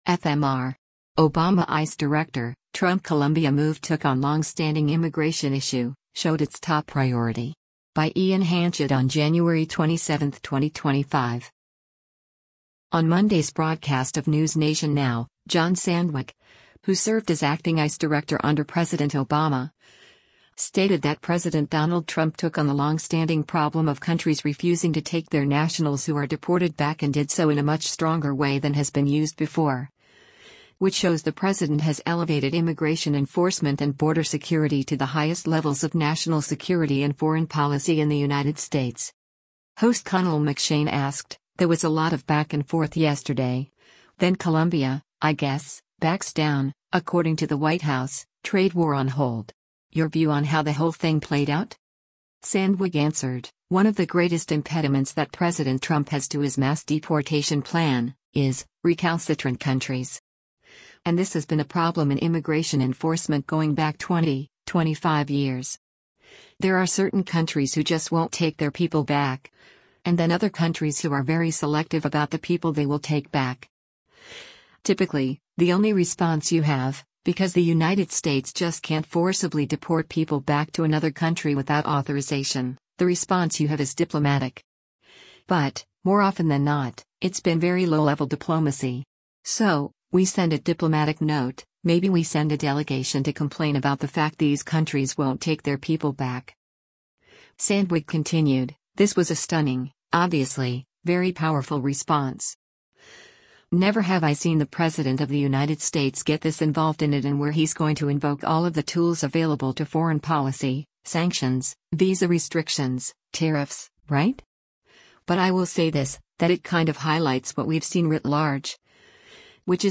On Monday’s broadcast of “NewsNation Now,” John Sandweg, who served as acting ICE Director under President Obama, stated that President Donald Trump took on the longstanding problem of countries refusing to take their nationals who are deported back and did so in a much stronger way than has been used before, which shows “the President has elevated immigration enforcement and border security to the highest levels of national security and foreign policy in the United States.”